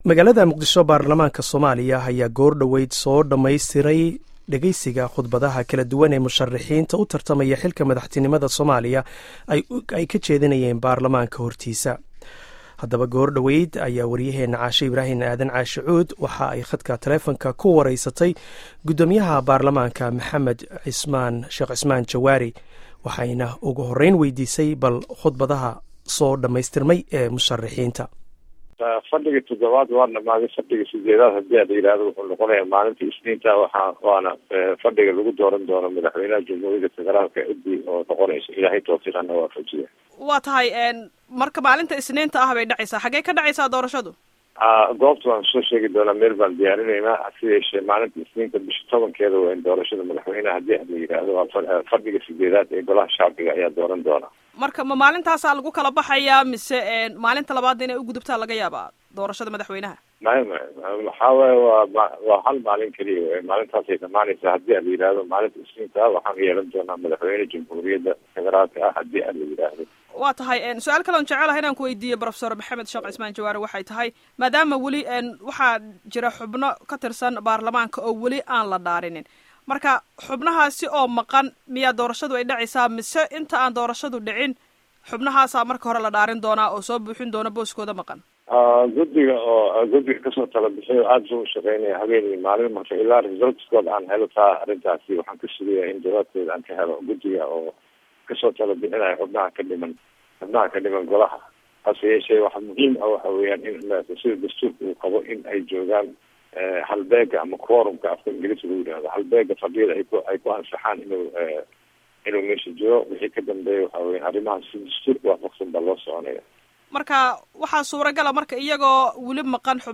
Dhageyso wareysiga Jawaari